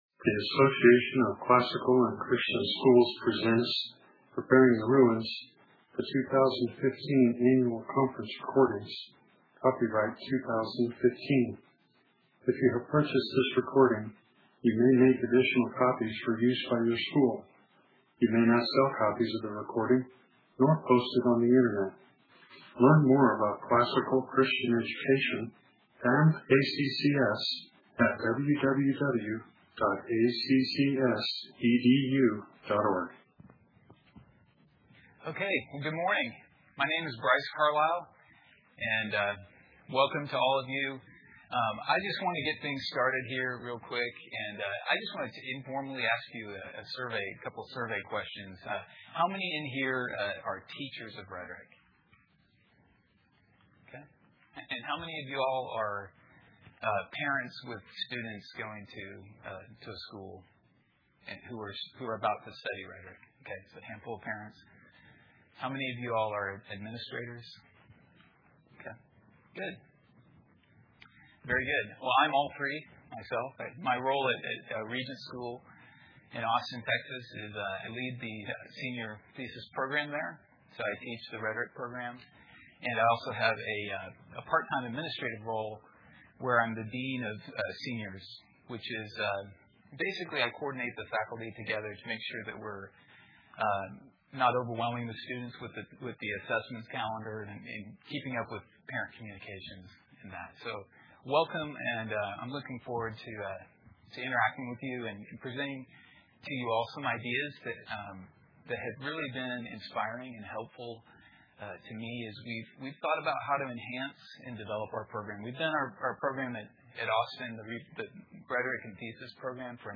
2015 Workshop Talk | 1:00:49 | 7-12, Rhetoric & Composition
Additional Materials The Association of Classical & Christian Schools presents Repairing the Ruins, the ACCS annual conference, copyright ACCS.